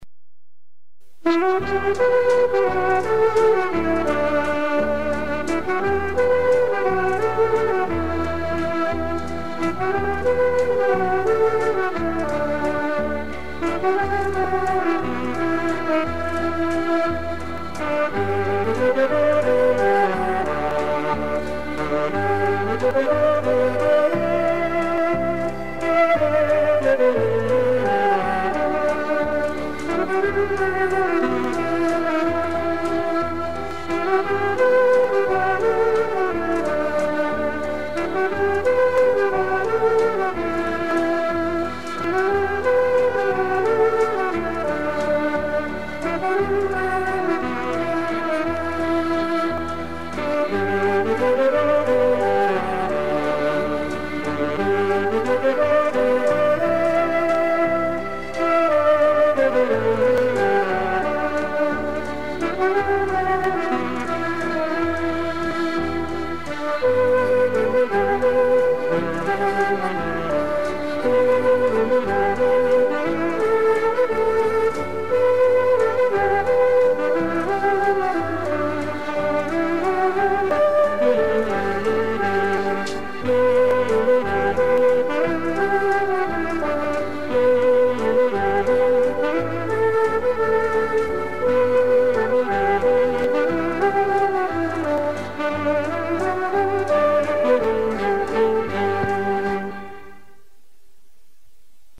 kategorie básně/ostatní